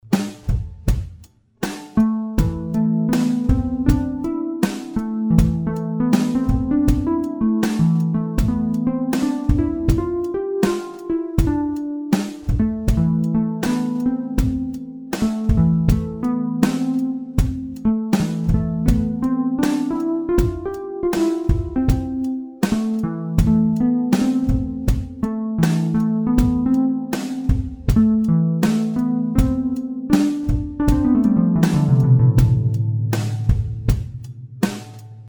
Der Sound ist transparent und Verzerrungen gibt es auch nicht.
harptime-pro-demo.mp3